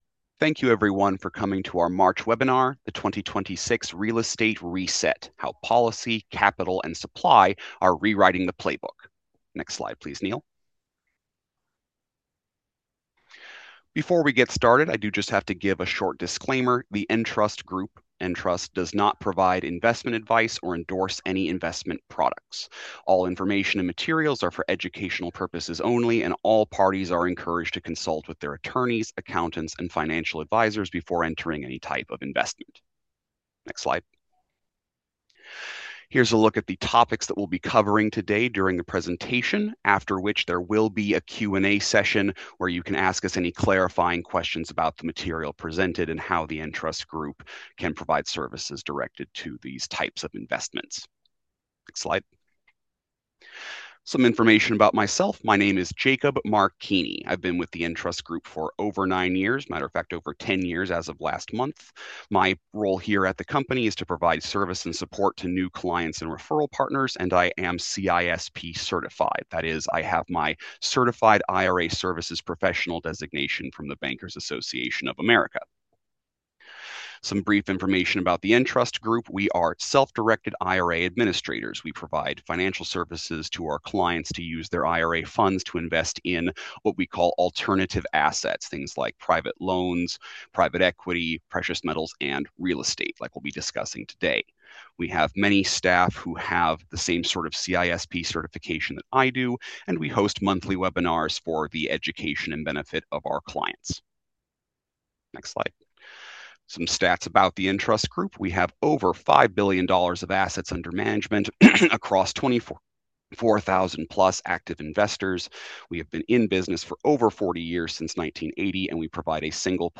In this educational training, we'll provide a data-driven deep dive into what's trending with real estate in 2026.